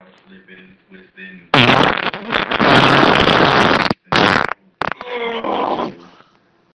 humongous-fart.mp3